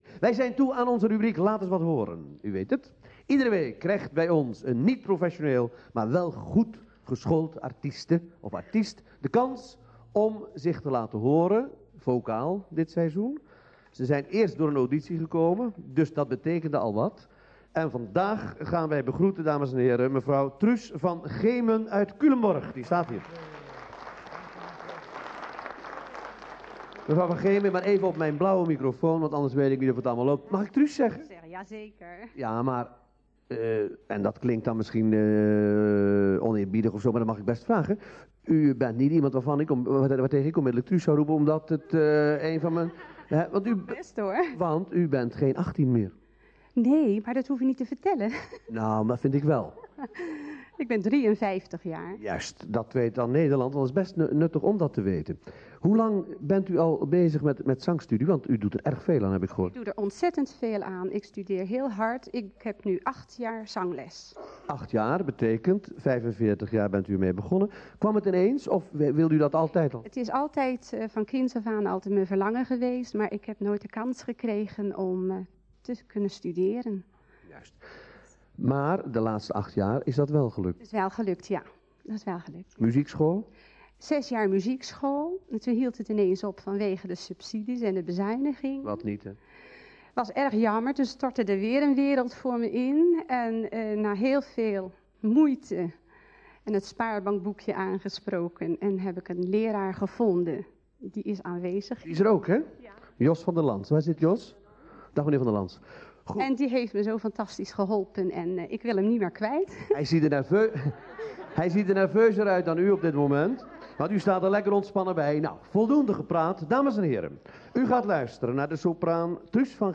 soprano
Aria Voi che sapete (Le nozze di Figaro, W.A.Mozart) plus interview (in Dutch)
Hilversum 1 (Dutch broadcasting station),1983
(aria inclusief interview vooraf en jurering achteraf)